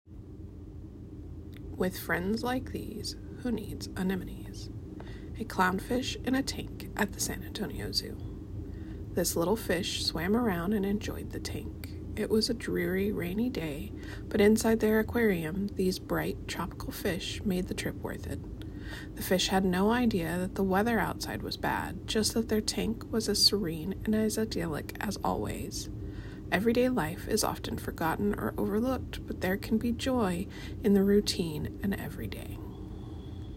Audio recording of artist statement